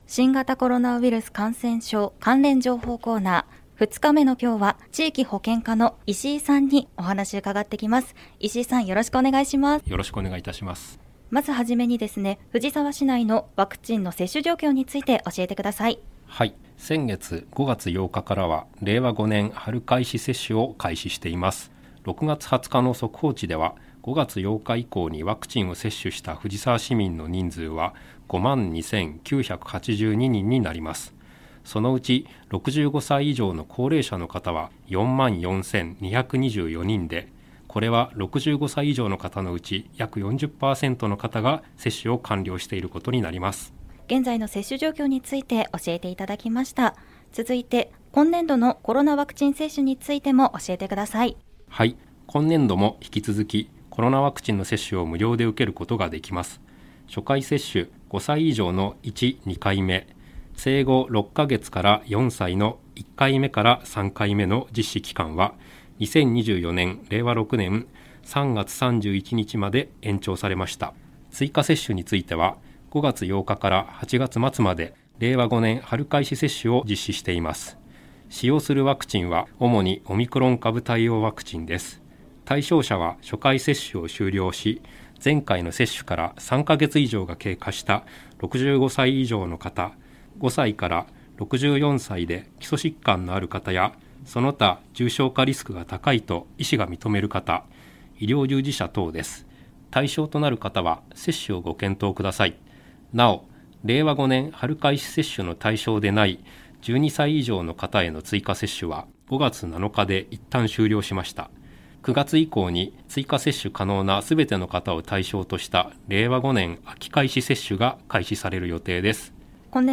令和5年度に市の広報番組ハミングふじさわで放送された「新型コロナウイルス関連情報」のアーカイブを音声にてご紹介いたします。